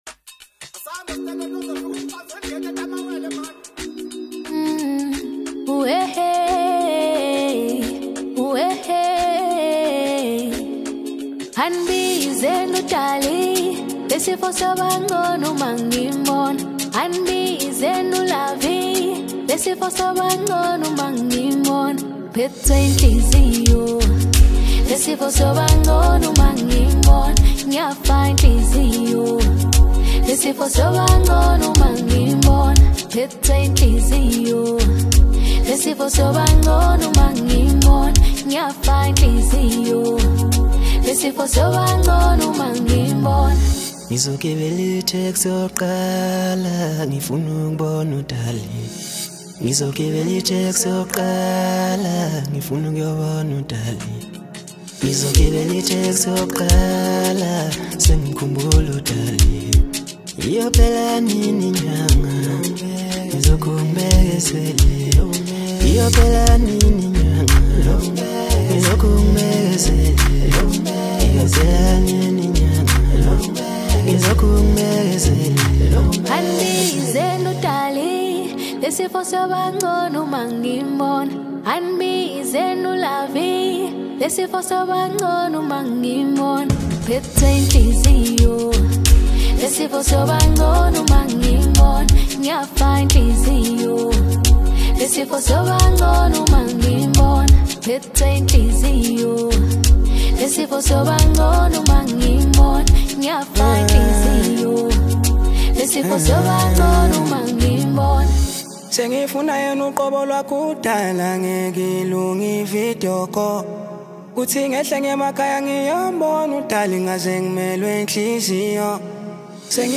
AFRO-POP Apr 07, 2026